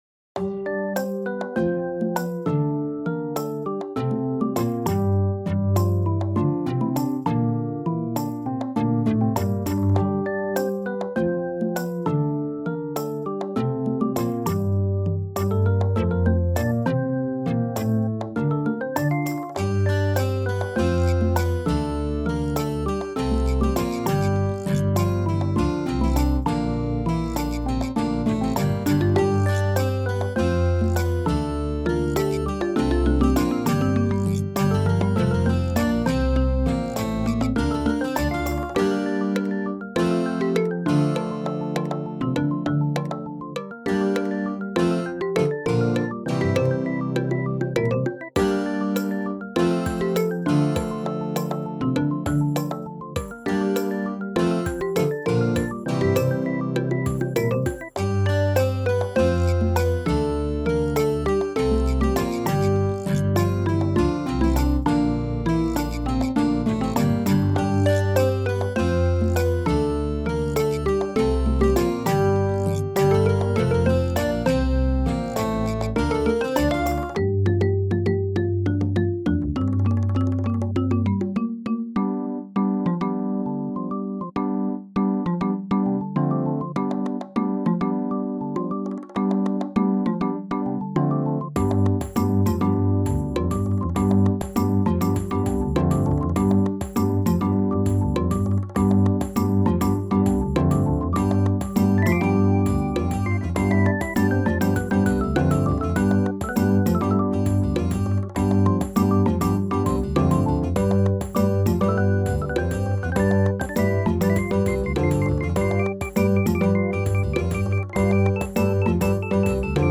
Percuss - Groovy imaginary medieval marimba score for Boohbah... or something.